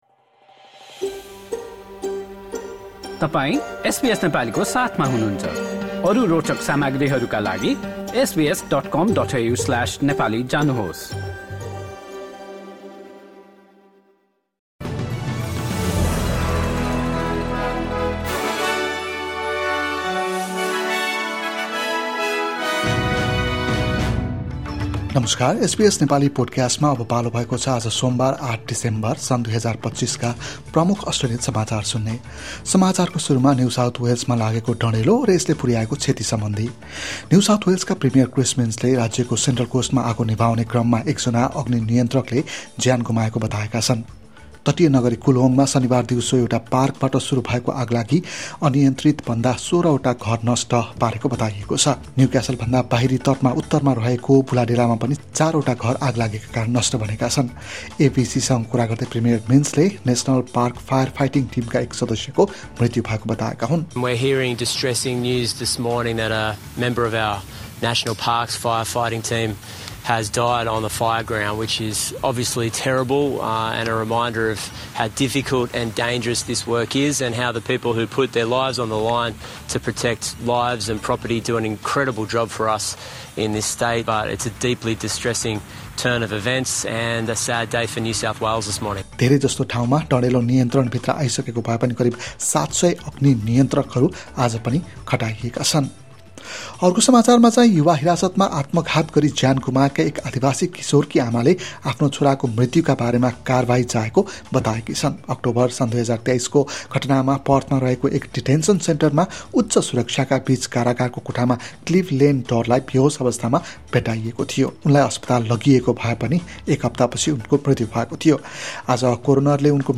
SBS Nepali Australian News Headlines: Monday, 8 December 2025